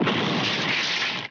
COMEDY - CRASH 01
Category: Sound FX   Right: Commercial